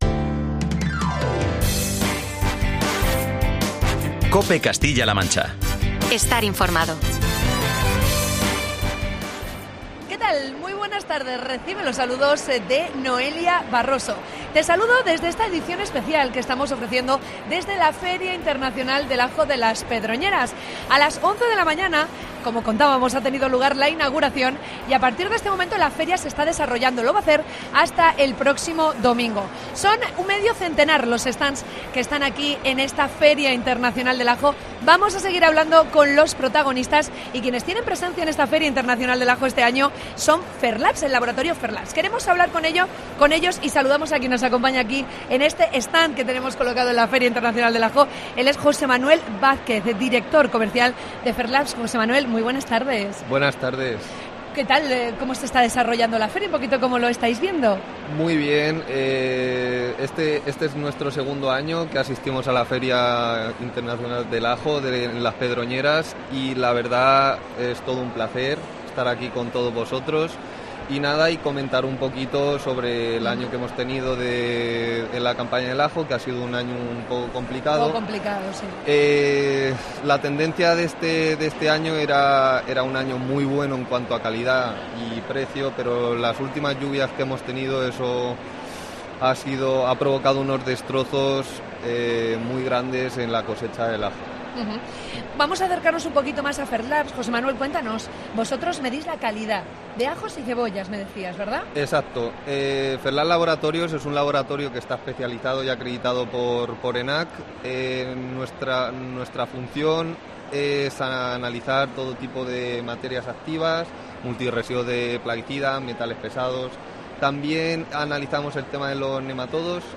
AUDIO: Programa especial desde Las Pedroñeras. Conoce los beneficios de esta maravillosa lilíacea.